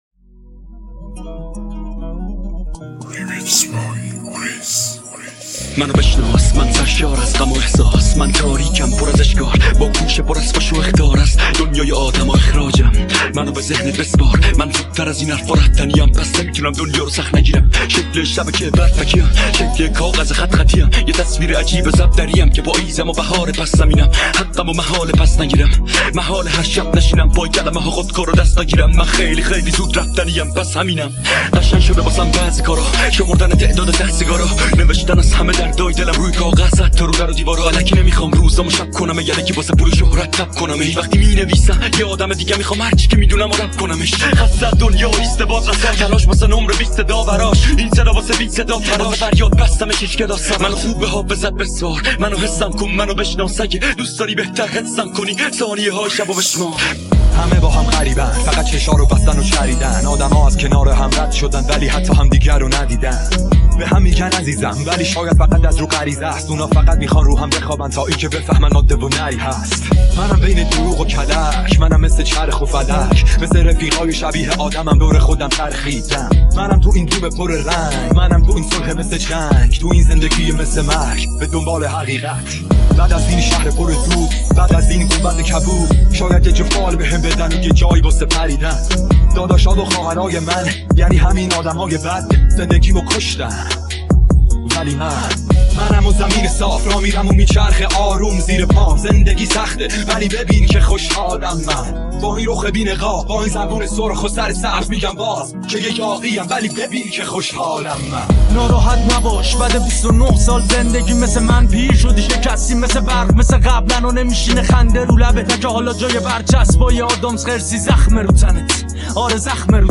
ریمیکس غمگین
رپ غمگین